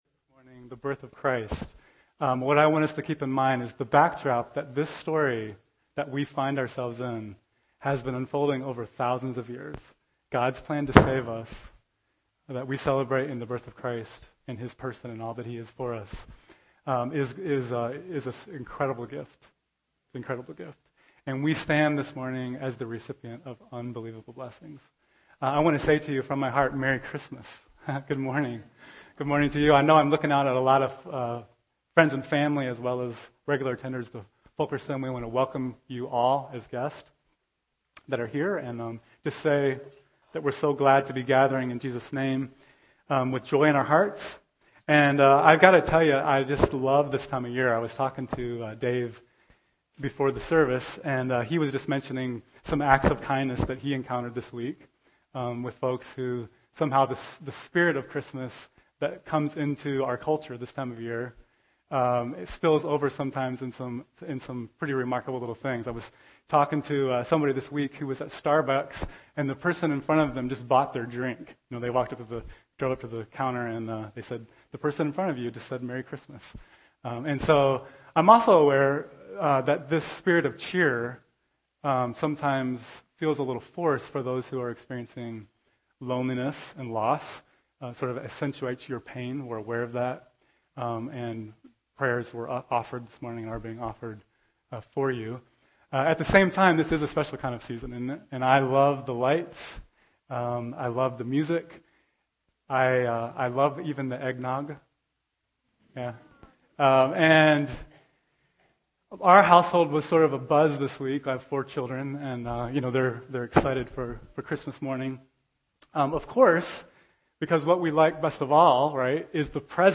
December 22, 2013 (Sunday Morning)